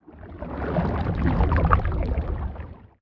Sfx_creature_glowwhale_swim_fast_05.ogg